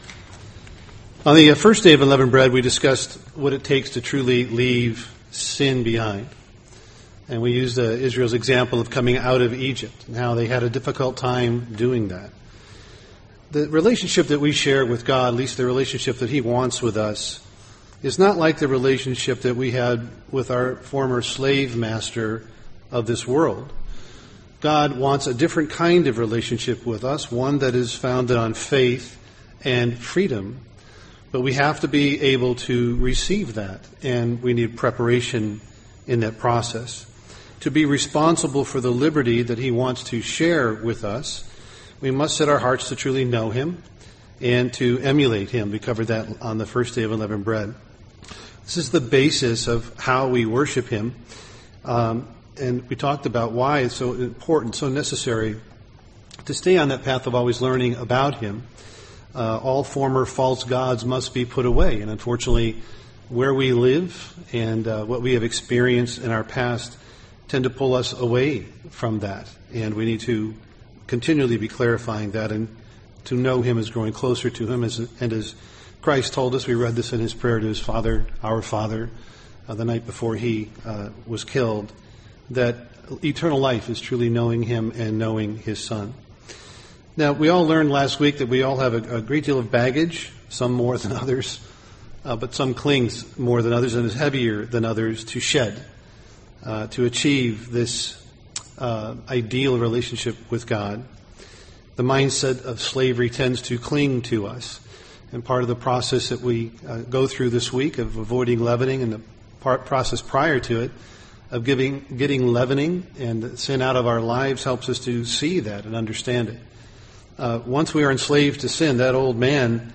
Given in Twin Cities, MN
UCG Sermon Unleavened Bread Egypt Studying the bible?